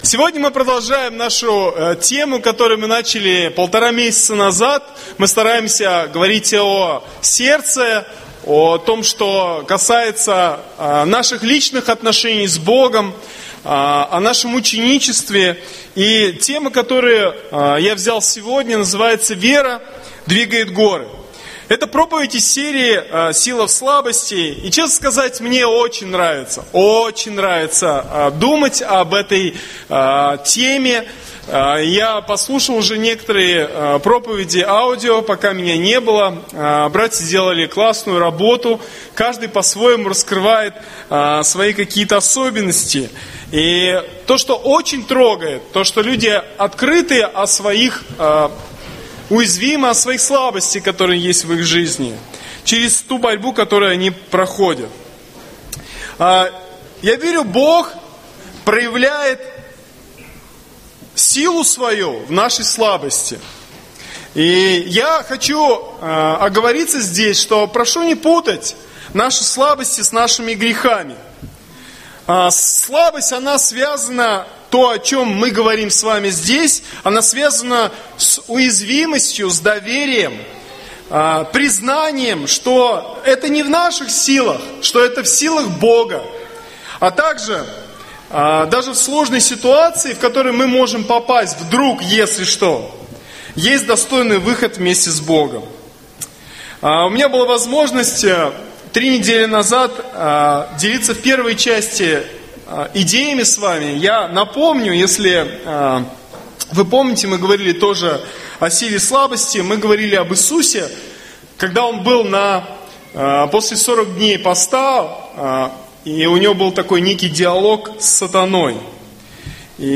Послушайте мой урок, который я делал на собрании церкви в Воскресенье.